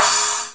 Cymbal Sound Effect
cymbal.mp3